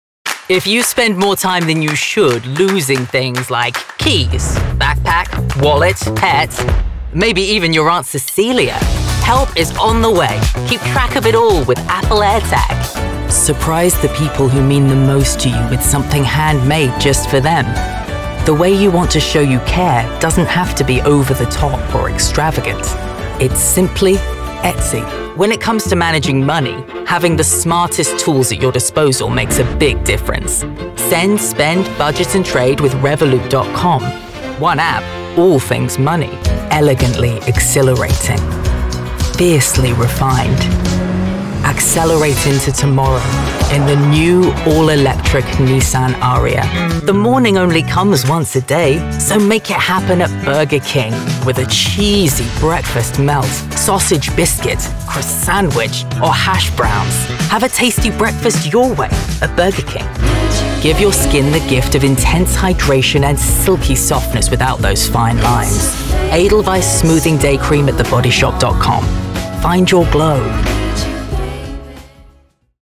Commercial demo
A warm, sincere and calm voice that envelops the listener in exciting possibilities.